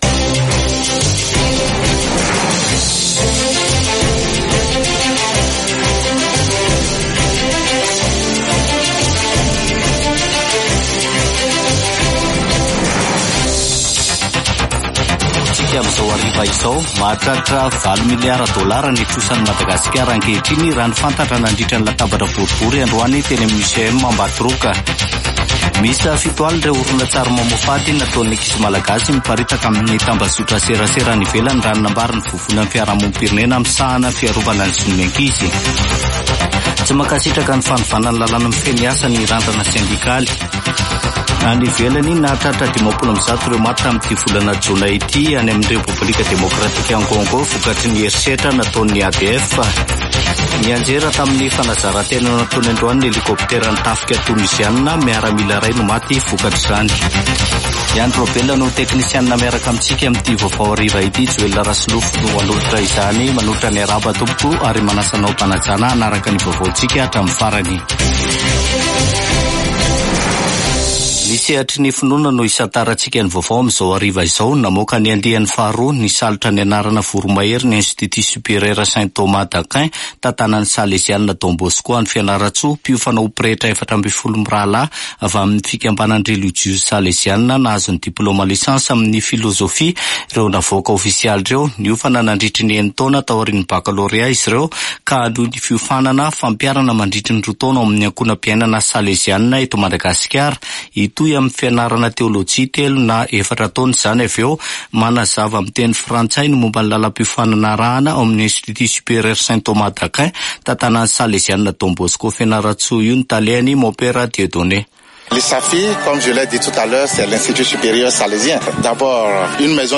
[Vaovao hariva] Zoma 21 jona 2024